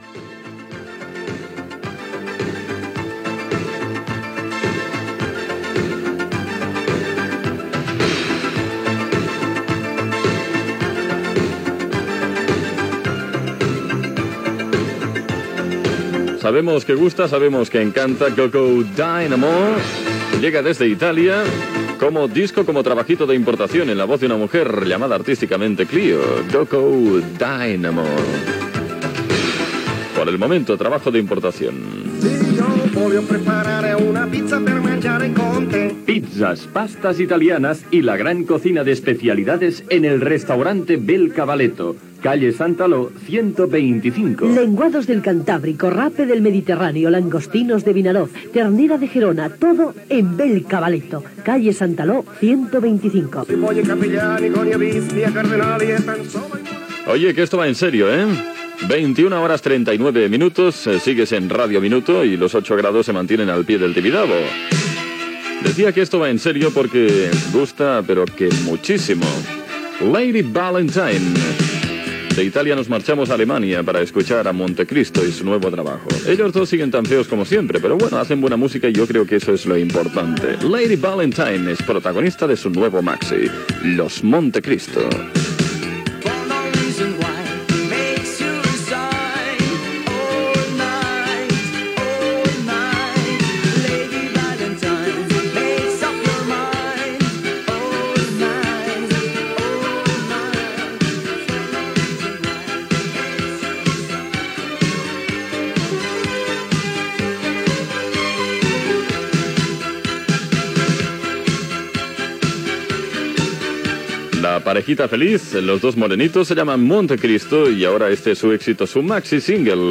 Tema musical, publicitat, hora, identificació, temperatura i tema musical, hora, identificació, informació des del Nou Camp del partit de futbol masculí Espanya Holanda, tema musical
Musical